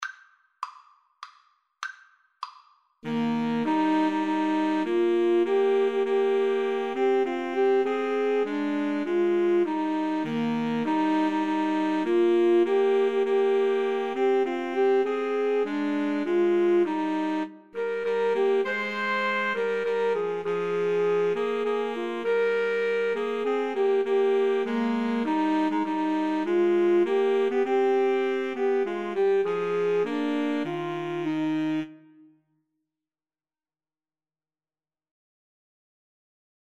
Alto SaxophoneTenor SaxophoneBaritone Saxophone
3/4 (View more 3/4 Music)
Eb major (Sounding Pitch) (View more Eb major Music for Woodwind Trio )
Woodwind Trio  (View more Easy Woodwind Trio Music)
Traditional (View more Traditional Woodwind Trio Music)